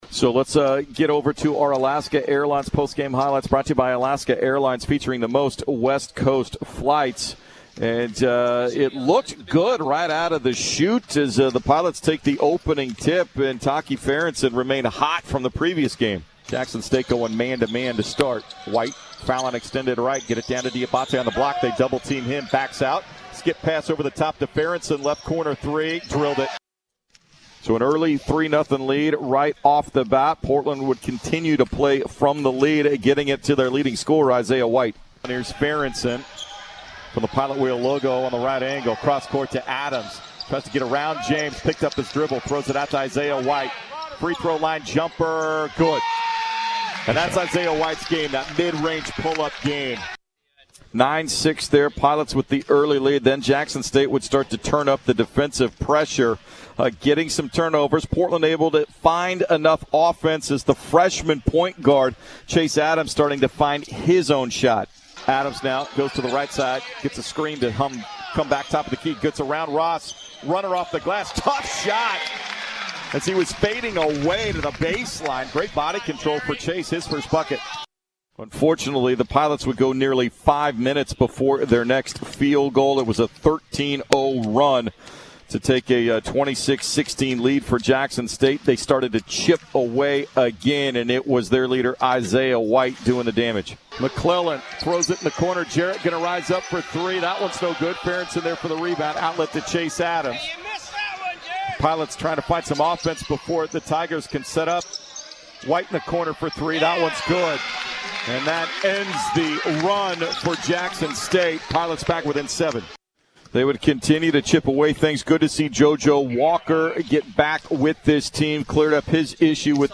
December 19, 2019 Radio recap and highlights from Portland's 73-63 loss to Jackson State on Dec. 19, 2019.